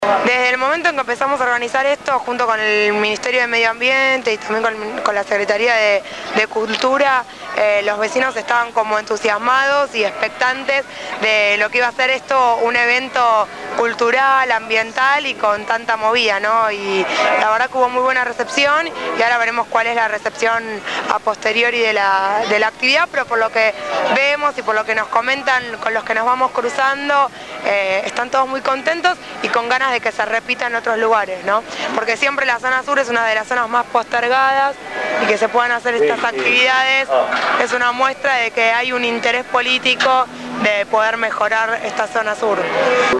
El sábado 4 de junio se llevó adelante la jornada solidaria «Somos Ambiente» en la Villa 21-24.